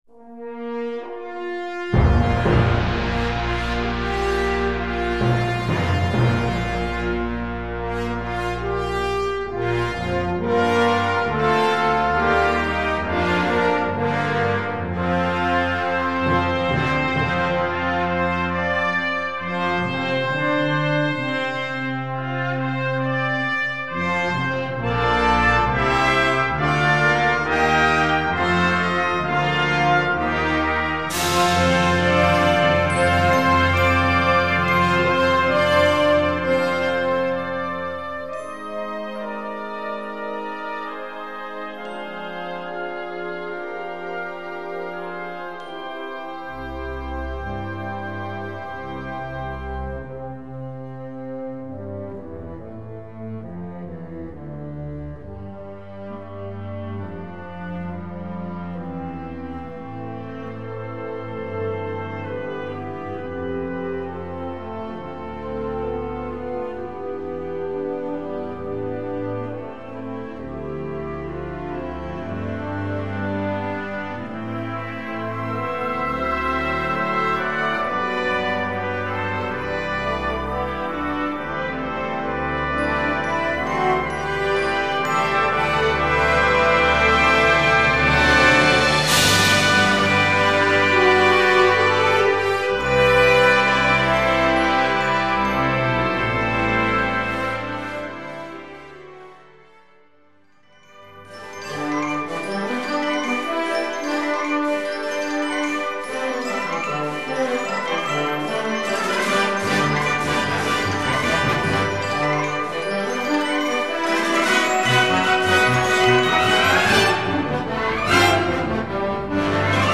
Concert Band ou Harmonie